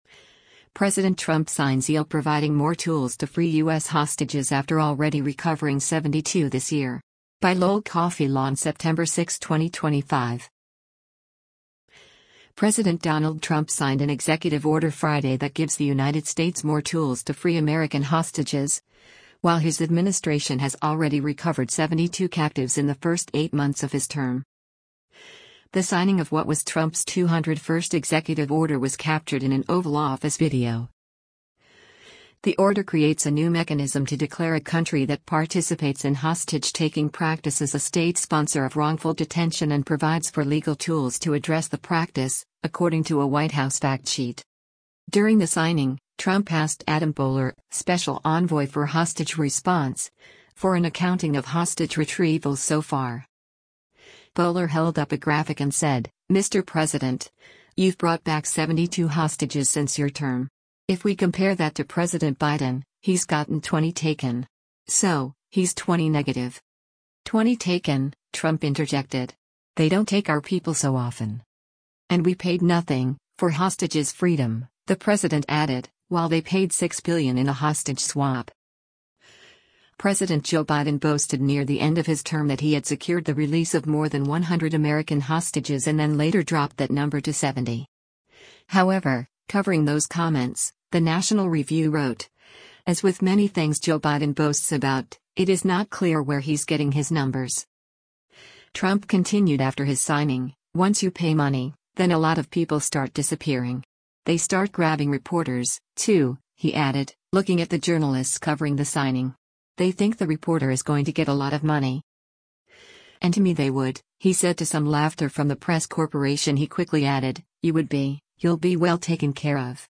The signing of what was Trump’s 201st executive order was captured in an Oval Office video.
“And to me they would,” he said to some laughter from the press corp.